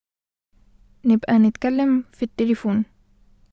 [ nebqa netkallem fe t-telefoon. ]